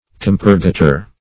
Compurgator \Com"pur*ga`tor\, n. [LL.]